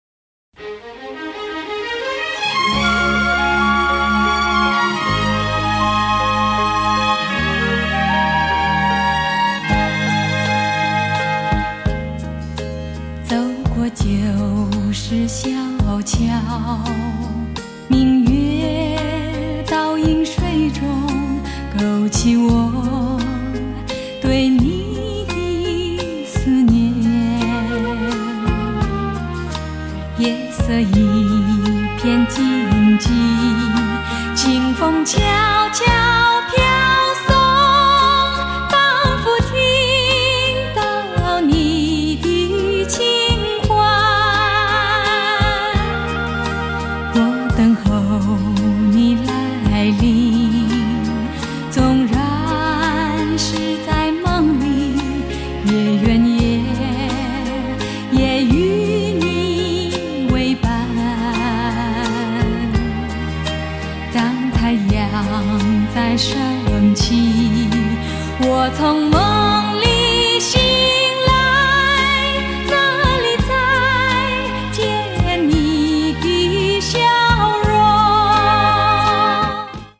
★ 虛無縹緲的天籟嗓音，蘊涵夢幻般的極致柔美！
★ 細膩幽邃的優雅歌聲，瀰漫氤氳般浪漫的韻味！